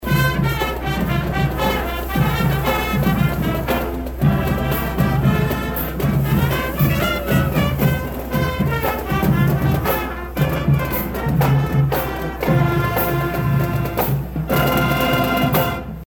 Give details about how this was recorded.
parade-2.mp3